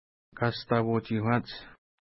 Pronunciation: ka:sta:pu:tʃi:hkwa:nts
Pronunciation